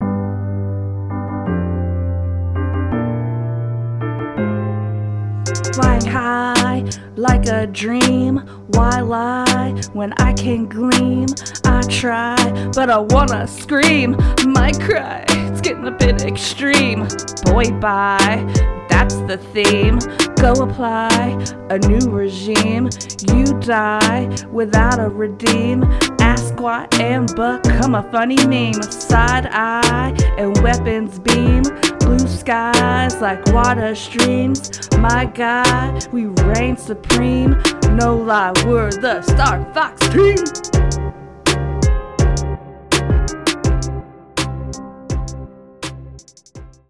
Rap from Episode 76: Star Fox 64 – Press any Button
Star-Fox-64-rap.mp3